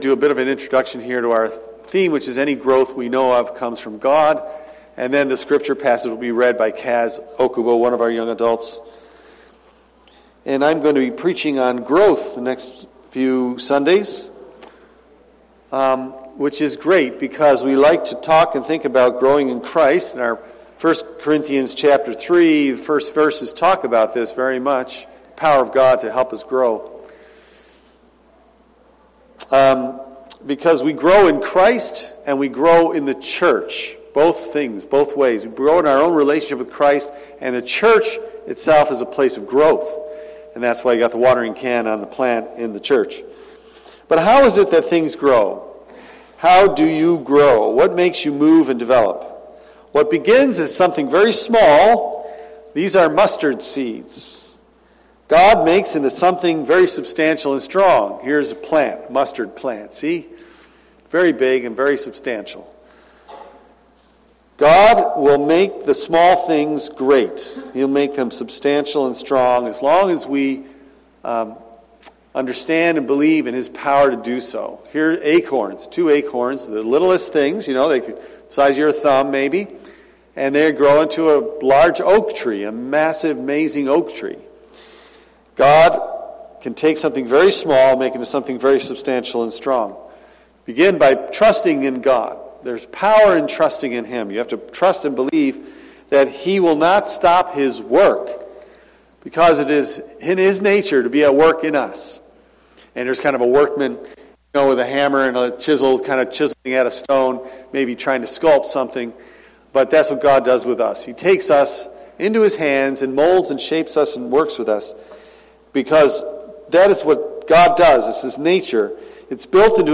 I am going to preach on growth in Christ and growth in the church for the next few weeks.